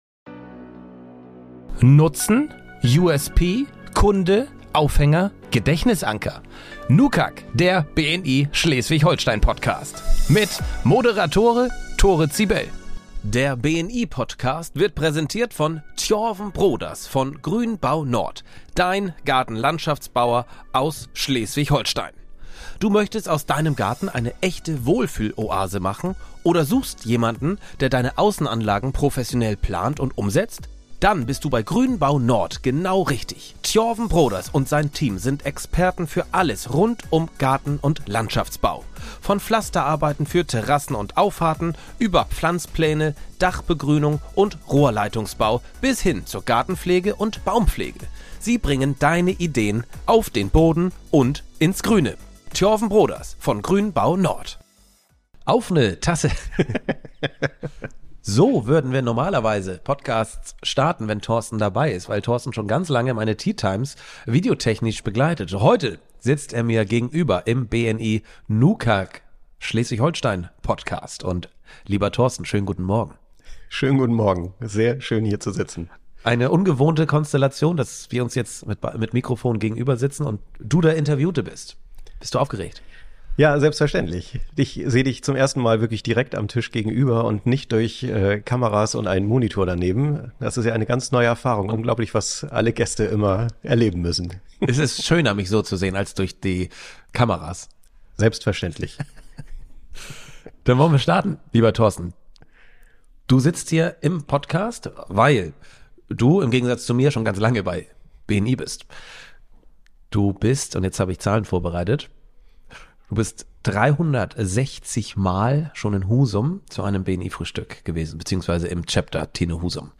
Ein Gespräch über Kreativität, Verantwortung, Weiterentwicklung – und darüber, wie man seine Erfahrungen aus ganz verschiedenen Lebensbereichen zu einem neuen, starken Weg zusammenführt.